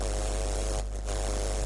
舔电缆" 吵闹声 curt 4
描述：受池田亮司的启发，我录下了我用手指触摸和舔舐连接到我的电脑线路输入口的电缆的声音。基本上是不同的fffffff，trrrrrr，和glllllll的最小噪音的声音...
Tag: 电缆 电气 电子 机械 噪声 信号